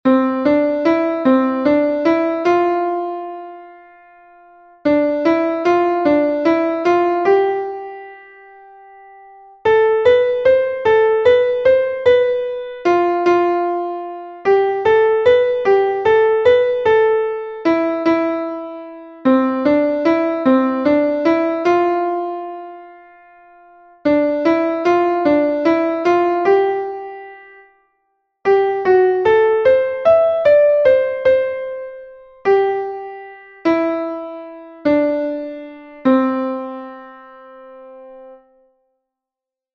Mucky song - Please do not stand on my balls